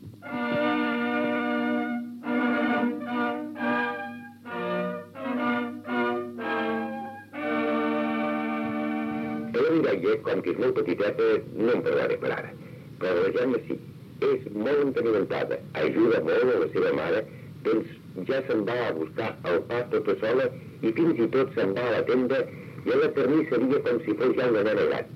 Infantil-juvenil